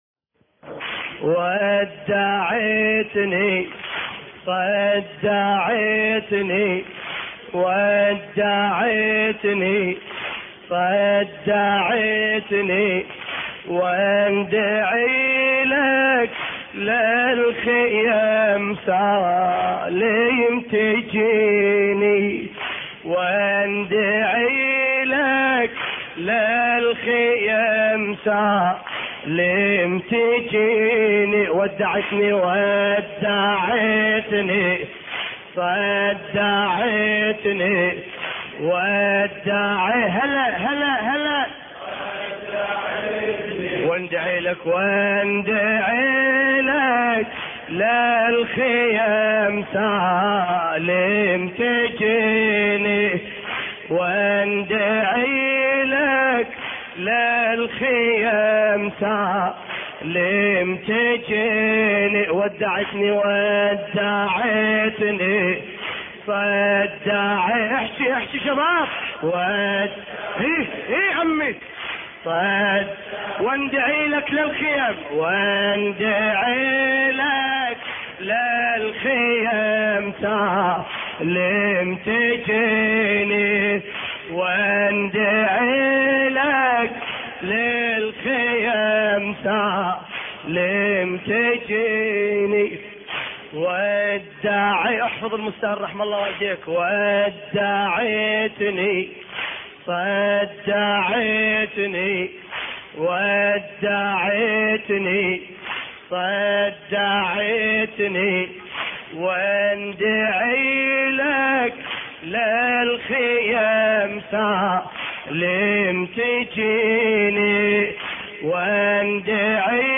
مراثي علي الأكبر (ع)